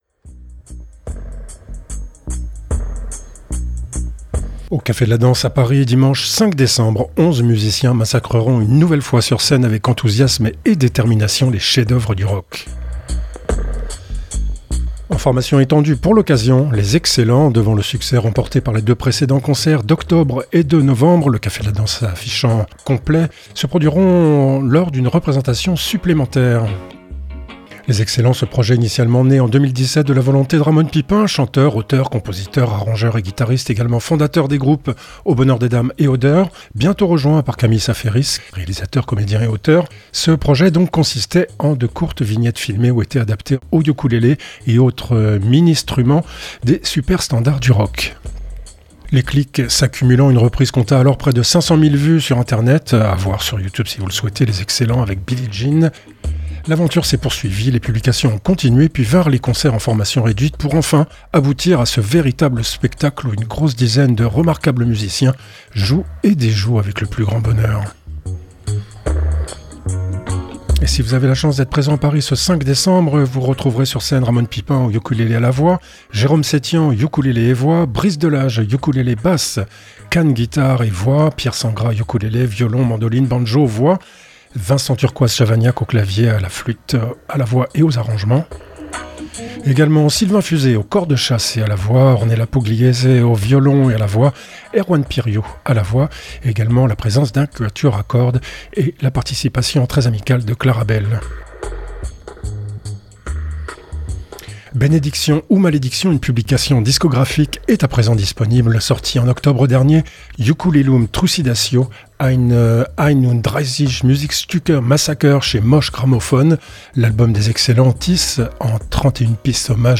Quelques heures avant le concert du 9 novembre dernier, Ramon Pipin donnait quelques explications quant à l’historique de ce massacre. Un reportage